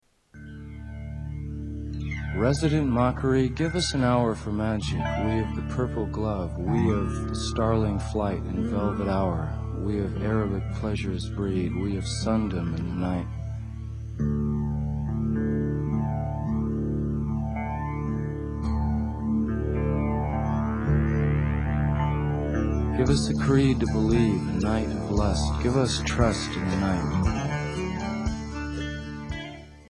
Tags: Jim Morrison quotes The Doors Jim Morrison Poems Jim Morrison spoken word Poetry CD